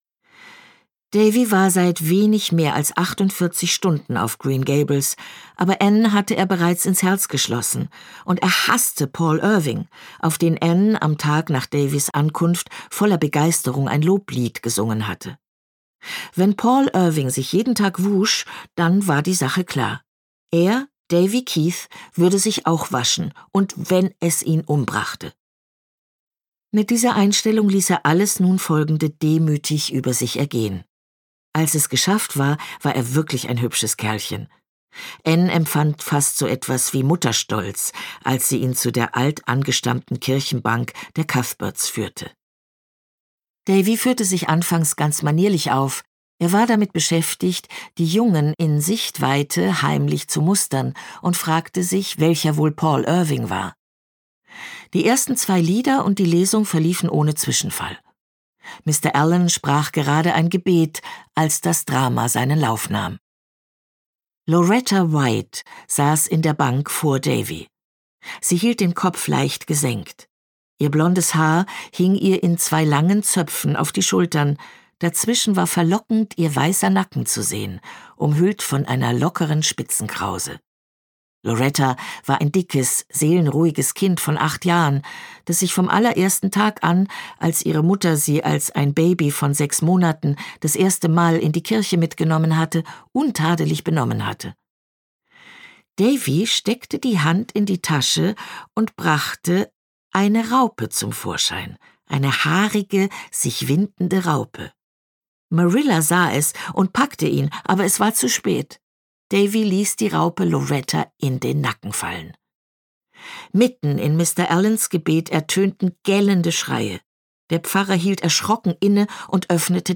Anne in Avonlea Lucy Maud Montgomery (Autor) Eva Mattes (Sprecher) Audio Disc 2023 | 1.